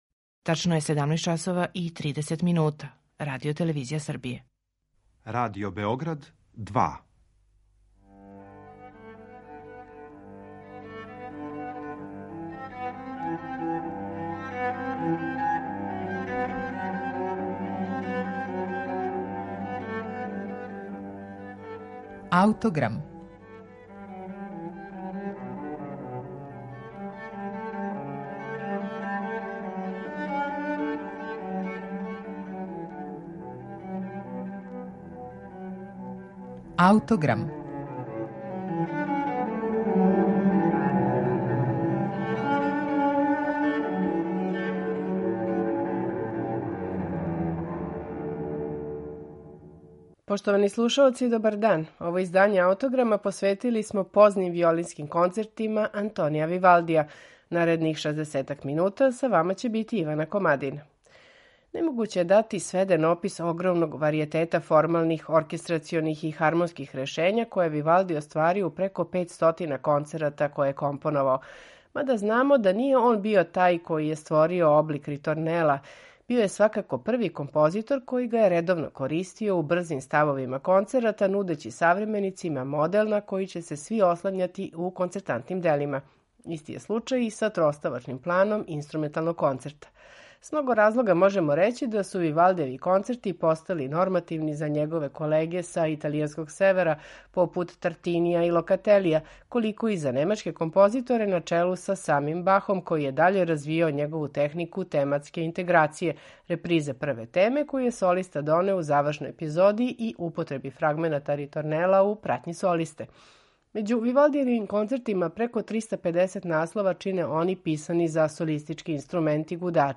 Четири позна концерта за виолину, гудаче и басо континуо Антонија Вивалдија
на оригиналним инструментима